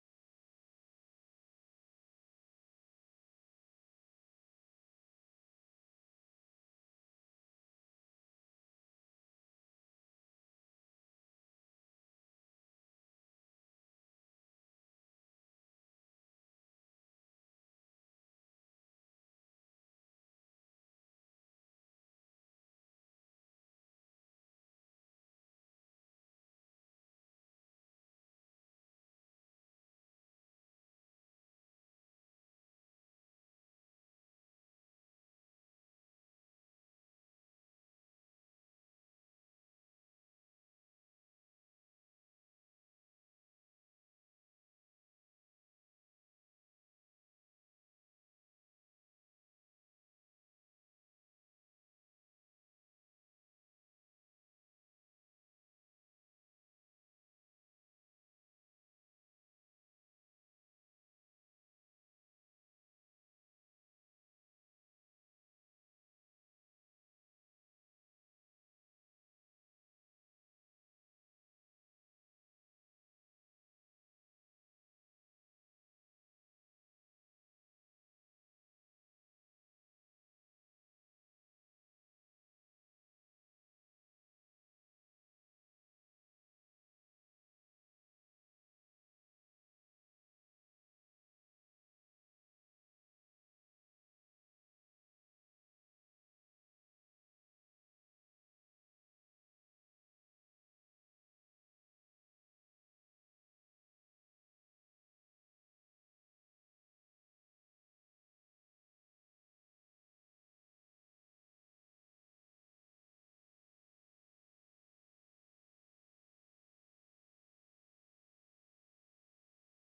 Dagboek en meditatie